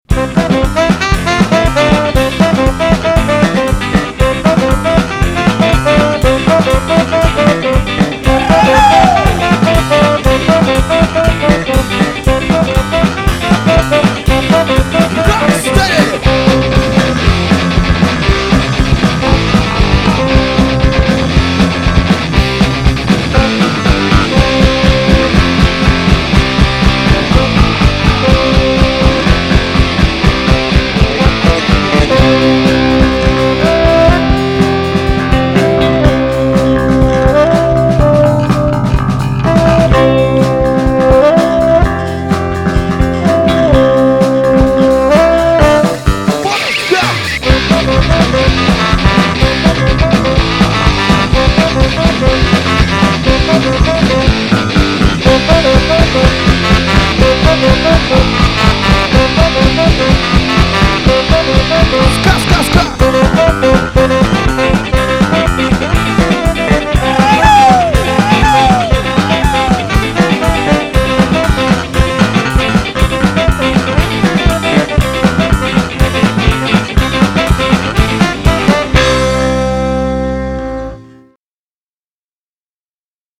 поп-панк